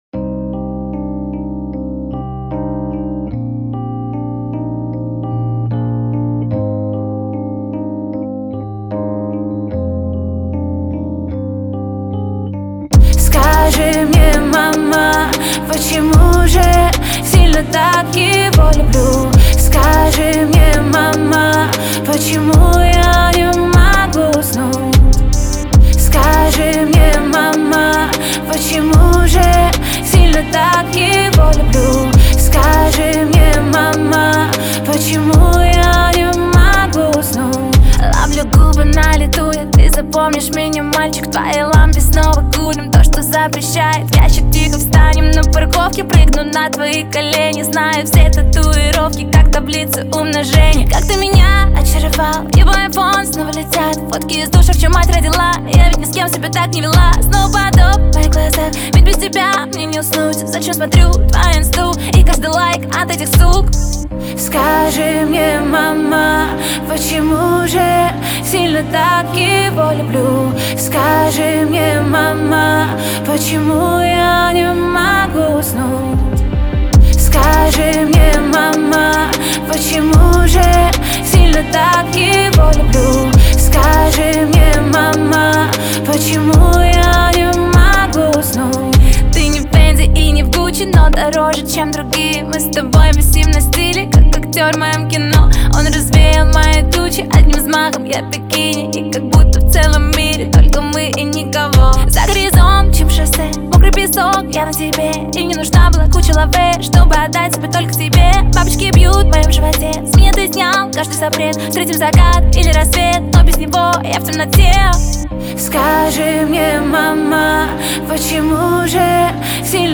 это яркая поп-песня